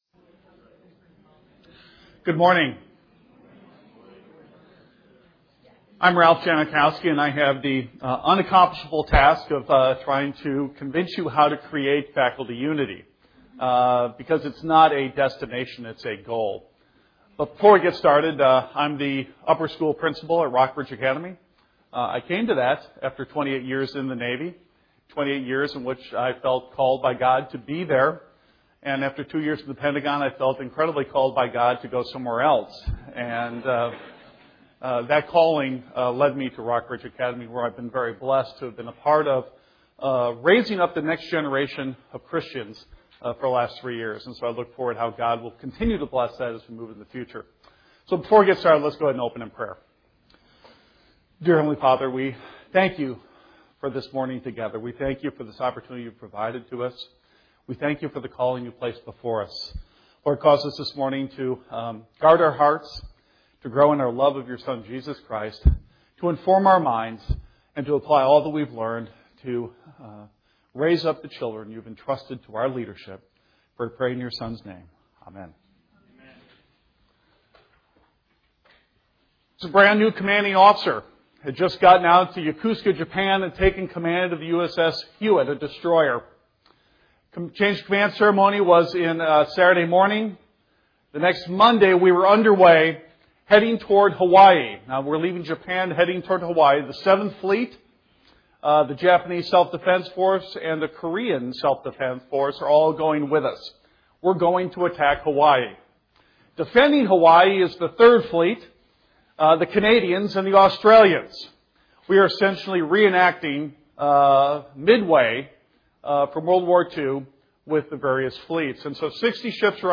2008 Workshop Talk | 1:01:36 | Leadership & Strategic